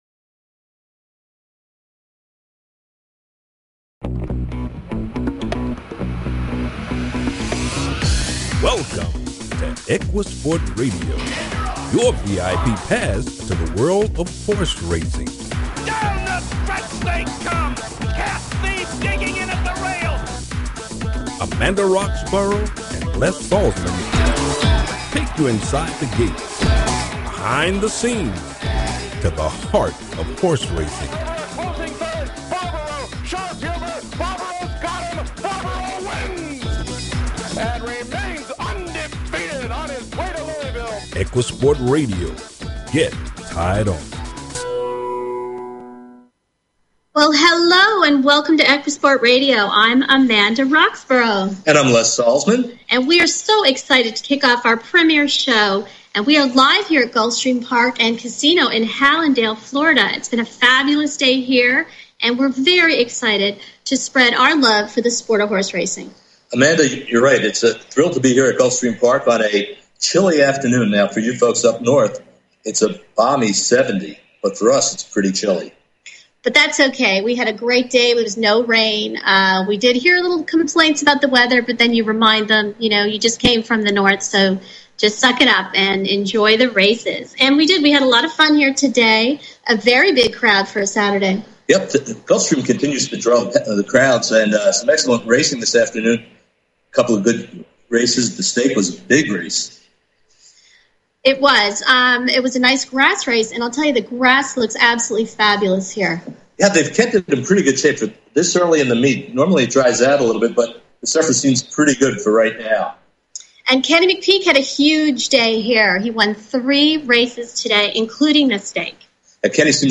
EquiSport News Debut 1/22/2010 from Gulfstream Park